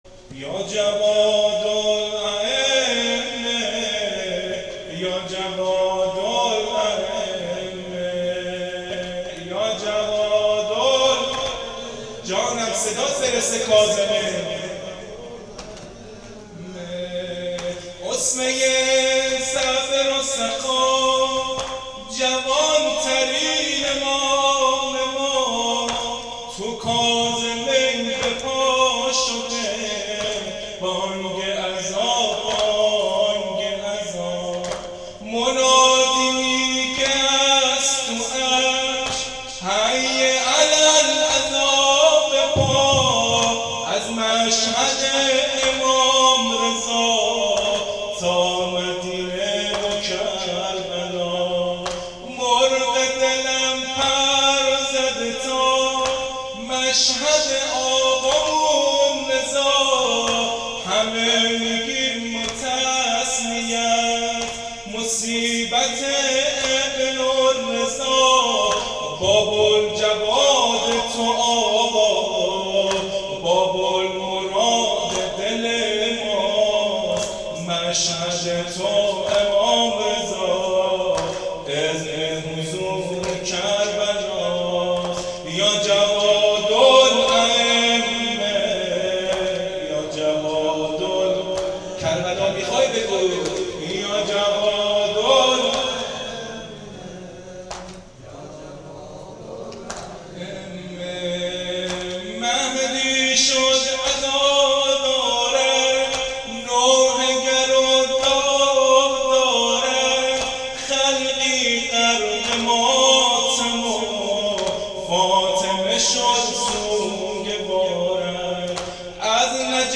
نوحه یا جواد الائمه ، شهادت امام تقی